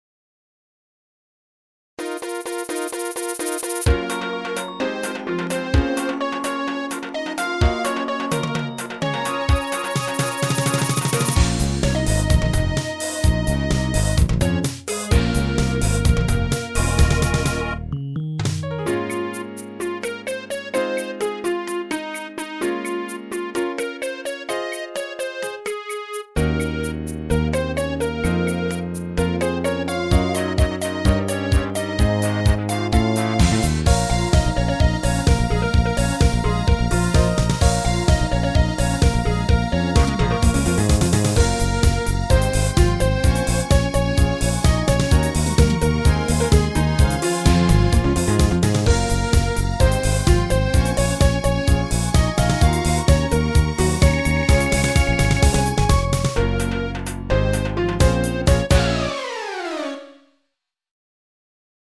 賑やかで派手な曲だがAP一発でその「哀愁」を漂わせている。